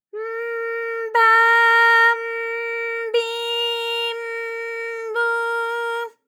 ALYS-DB-001-JPN - First Japanese UTAU vocal library of ALYS.
b_m_ba_m_bi_m_bu.wav